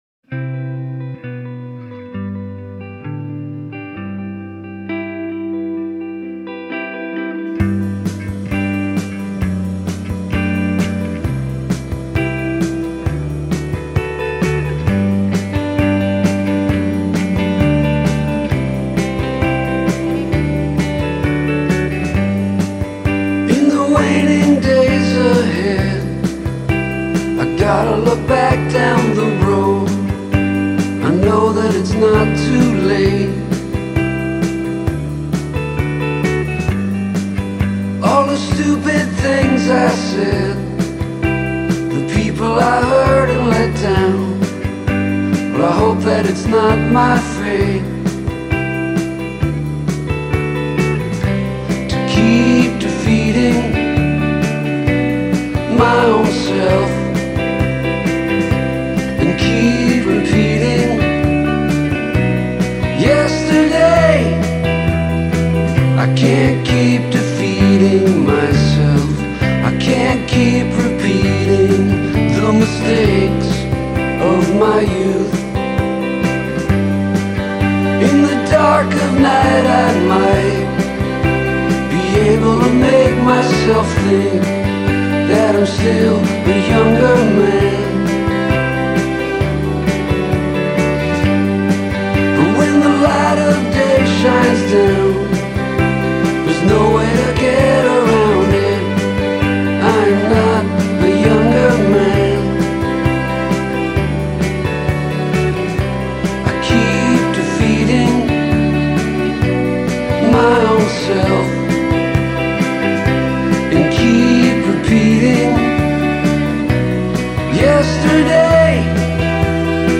aber wen stört das schon bei solch herzerweichenden songs?